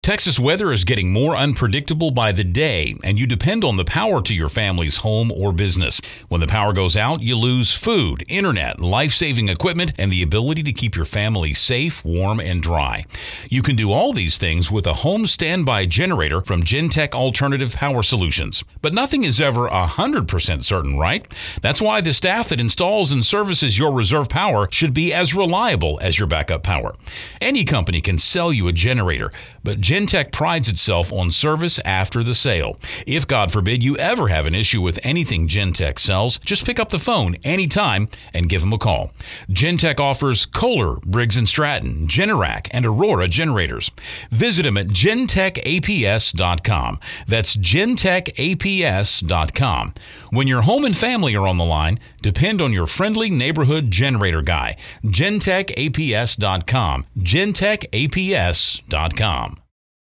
commercial.mp3